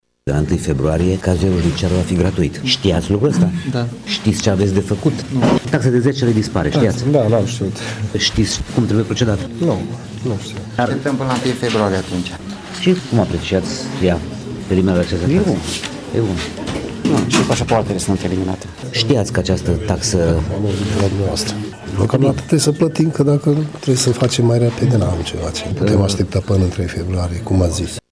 Nu toți cei prezenți la ghișeul de eliberare cazierului judiciar de la sediul Poliției Județene Mureș știau de eliminarea taxei de 10 lei.